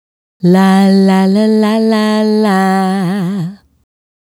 La La La 110-G.wav